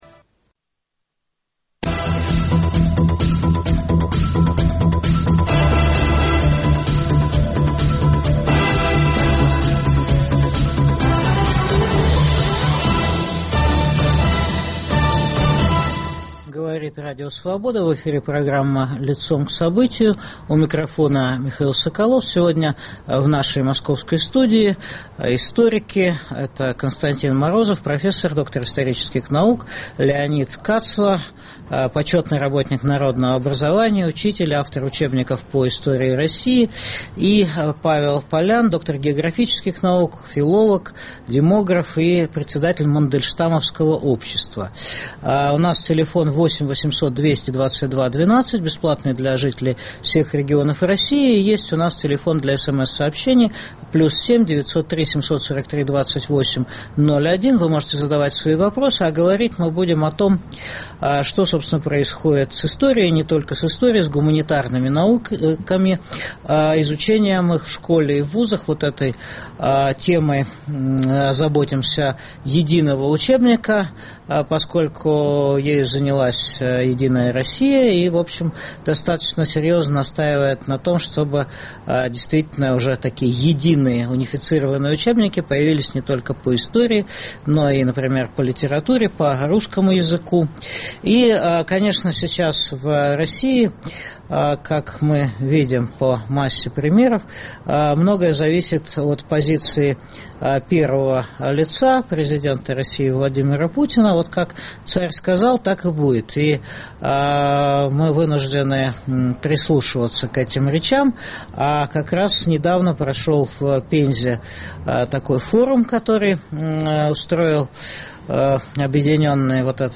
В дискуссии участвуют